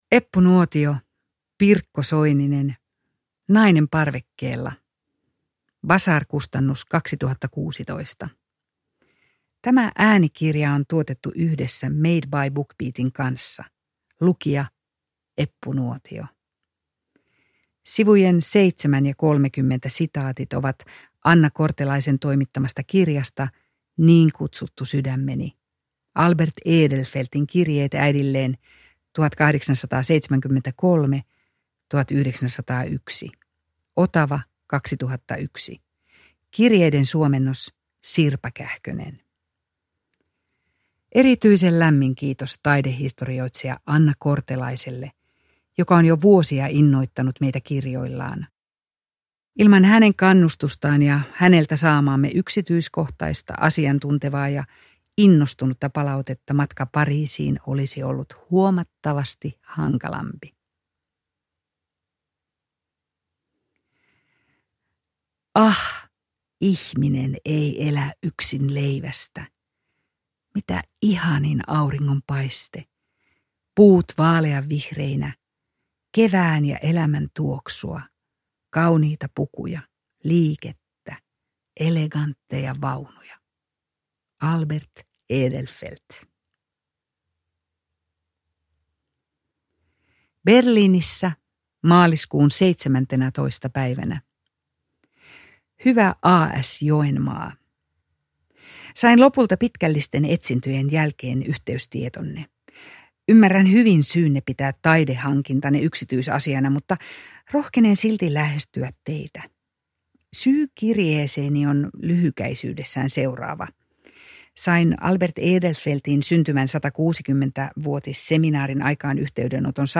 Nainen parvekkeella – Ljudbok – Laddas ner
Uppläsare: Eppu Nuotio